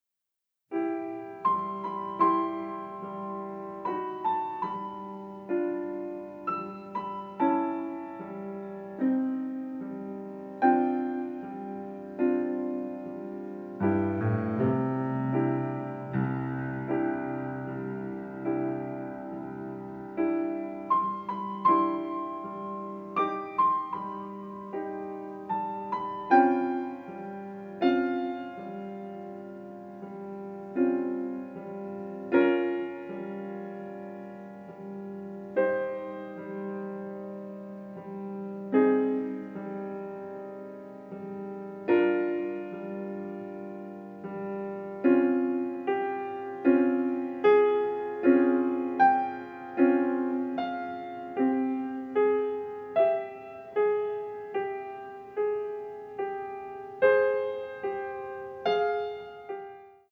dynamic score